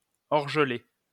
Orgelet (French pronunciation: [ɔʁʒəlɛ]